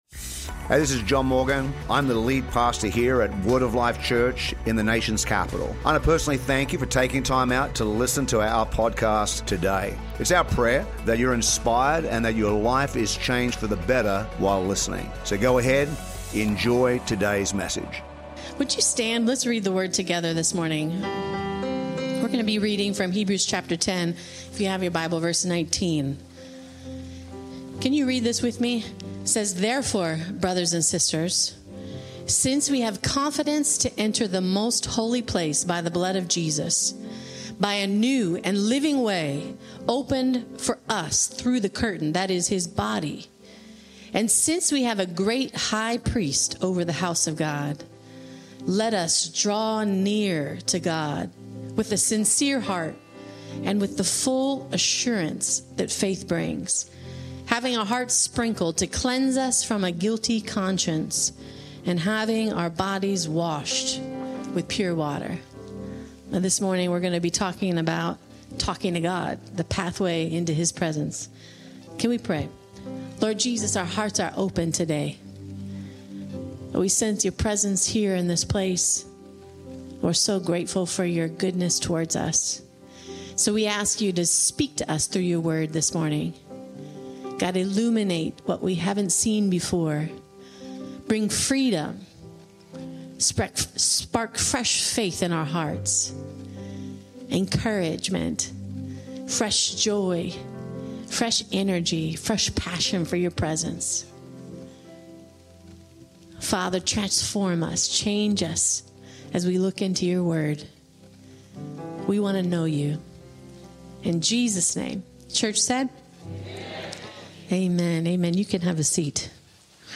This powerful sermon unpacks the secrets of prayer, revealing how it boosts your mood, fights anxiety, and even eases pain! Dive into the intimacy with God that outshines meditation, unlock the surprising truth about what God really wants from your prayers, and discover how His unconditional love washes away shame and shamelessly lifts you up.